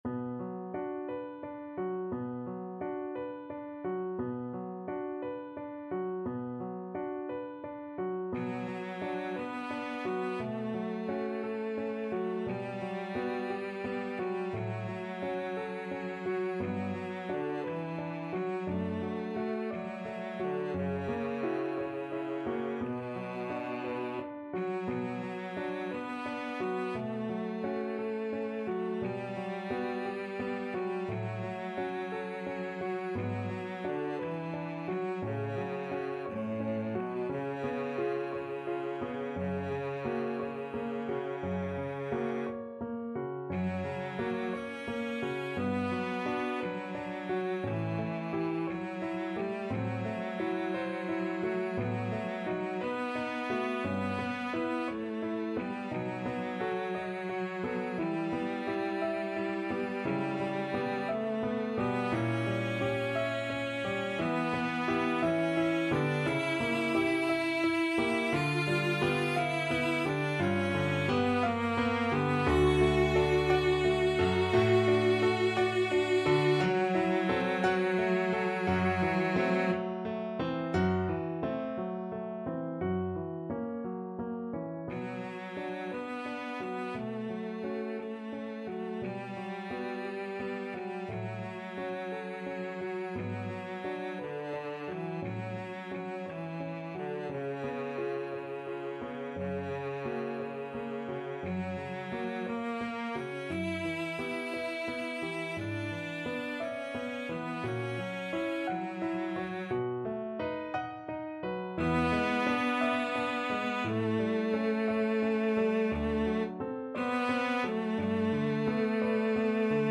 Cello version Cello Classical